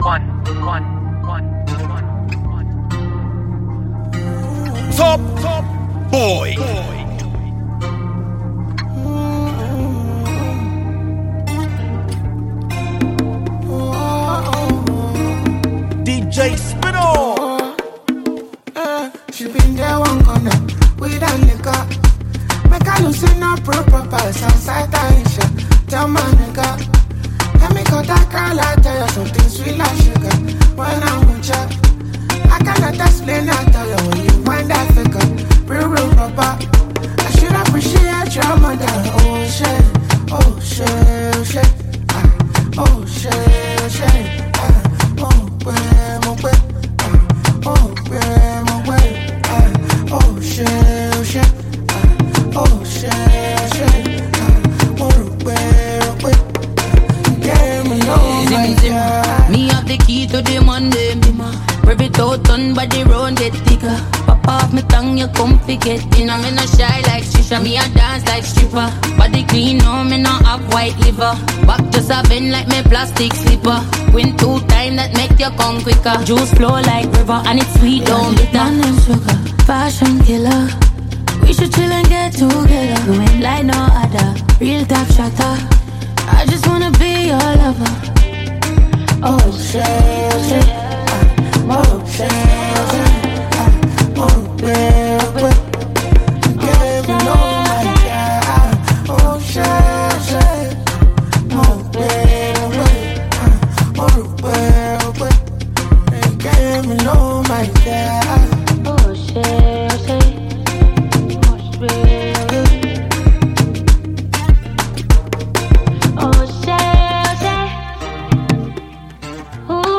a catchy anthem this summer